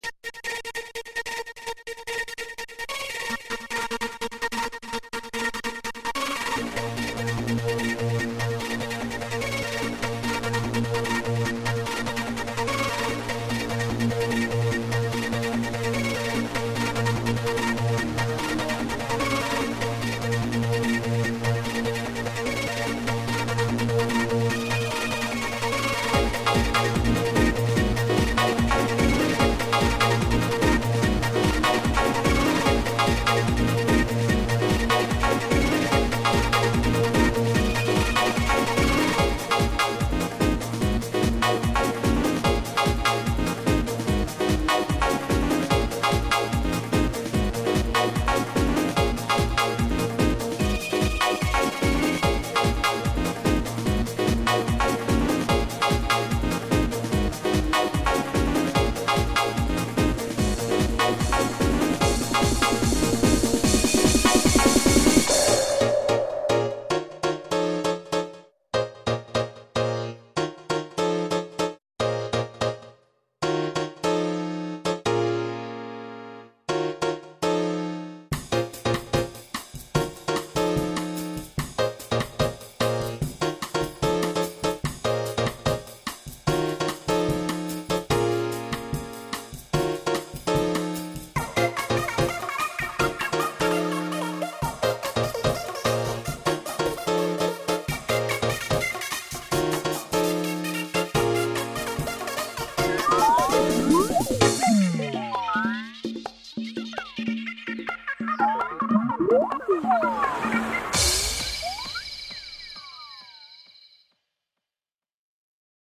Nicht-Klassik
Hat so nen bisschen was von Zukunftsvision und Weltraumspiel